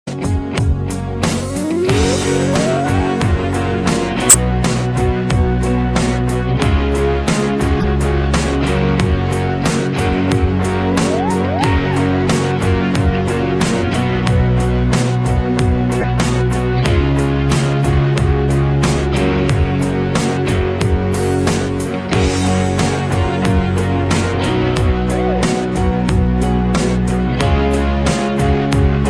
رینگ تون
جزو آهنگهای قوی و ریتمیک